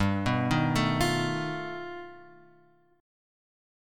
G Augmented 9th